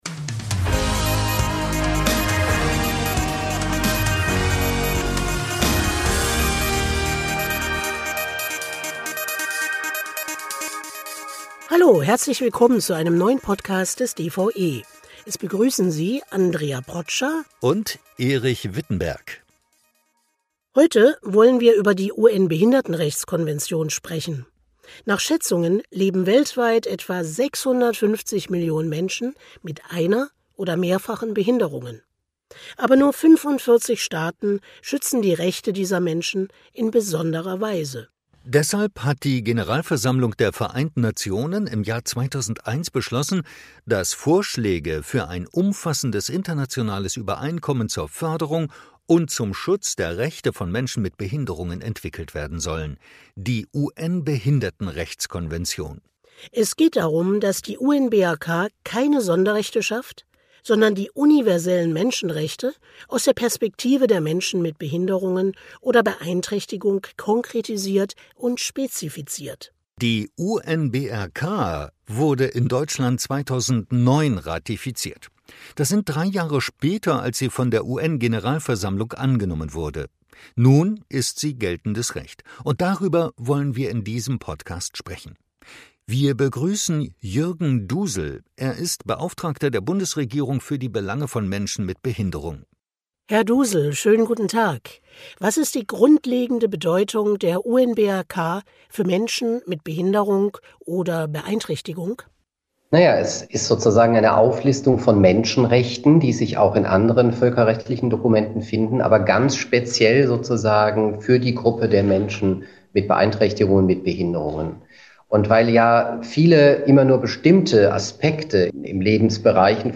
Ein Gespräch über die UN-Behindertenrechtskonvention und deren grundlegende Bedeutung für alle Lebensbereiche.